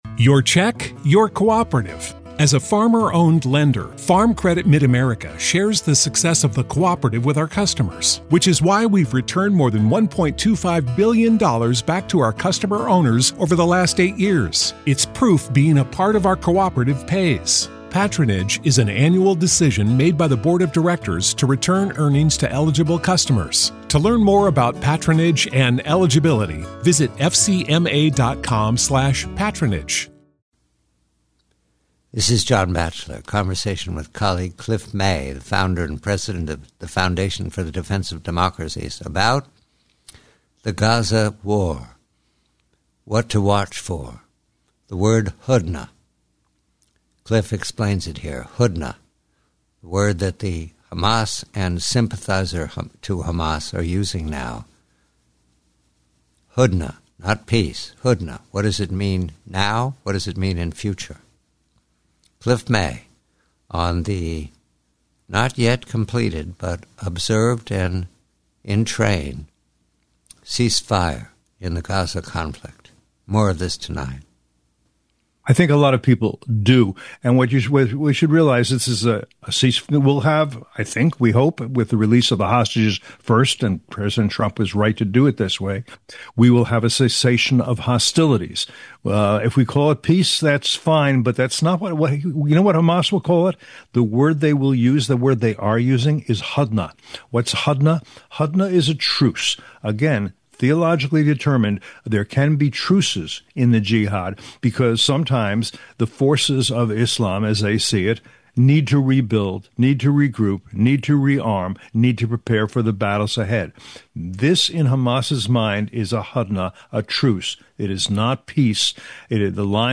Headliner Embed Embed code See more options Share Facebook X Subscribe HEADLINE: Understanding the Term "Hudna" in the Gaza Conflict GUEST NAME: Cliff May SUMMARY: John Batchelor speaks with Cliff May about the Gaza War term "hudna," used by Hamas and sympathizers, which refers to a truce, not peace. Theologically, a hudna allows the forces of Islam to rebuild, rearm, and prepare for future battles. In Hamas's view, the observed ceasefire is merely a temporary regrouping, not genuine peace.